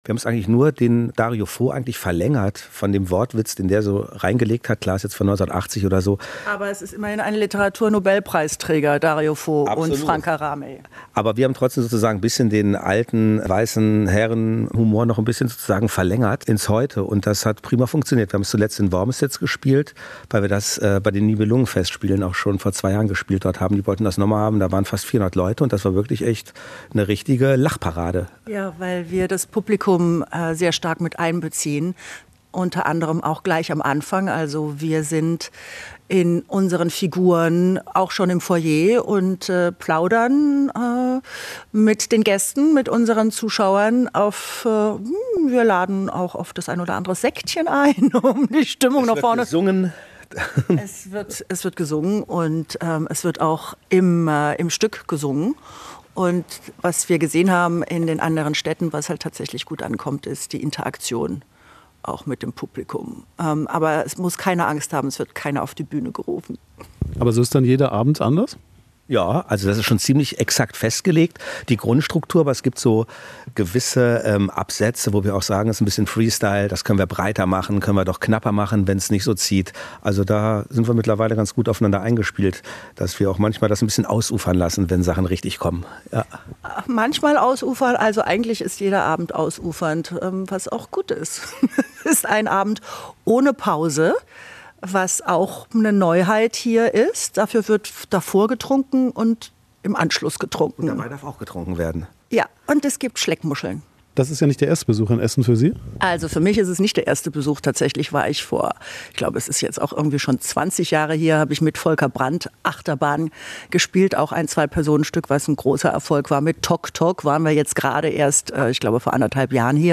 Im Interview sagt Alexandra Kamp warum sie Essen schon früher lieben gelernt hat.